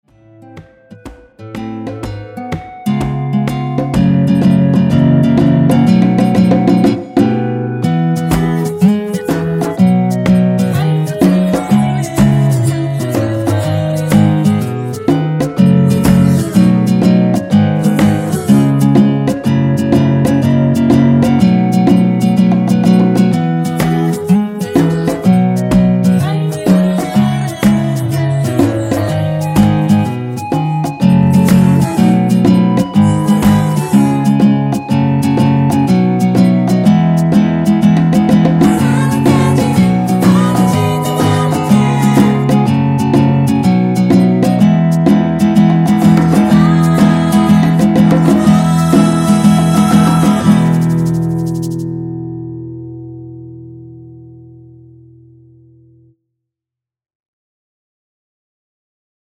전주 없이 시작 하는곡이라 노래 하시기 편하게 전주 2마디 많들어 놓았습니다.(일반 MR 미리듣기 확인)
원키 멜로디와 코러스 포함된 MR입니다.(미리듣기 확인)
앞부분30초, 뒷부분30초씩 편집해서 올려 드리고 있습니다.